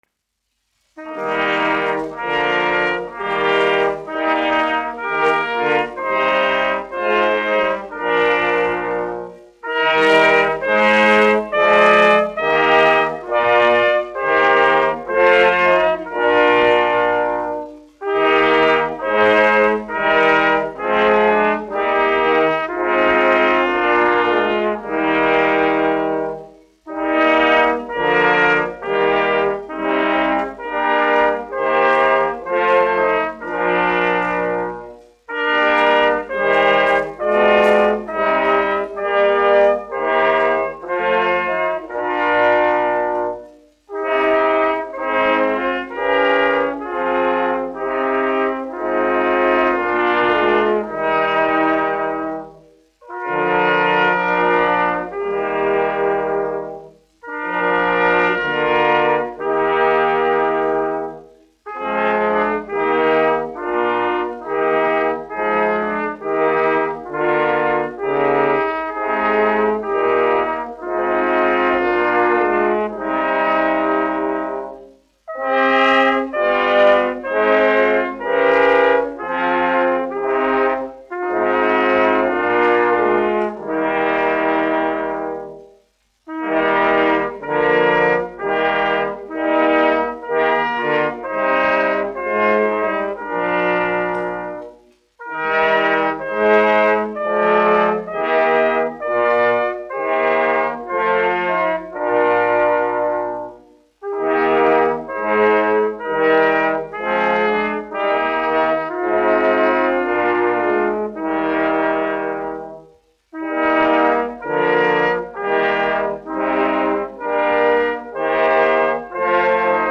Latvijas Nacionālā opera Pūtēju kvartets, izpildītājs
1 skpl. : analogs, 78 apgr/min, mono ; 25 cm
Korāļi
Pūšaminstrumentu kvarteti
Latvijas vēsturiskie šellaka skaņuplašu ieraksti (Kolekcija)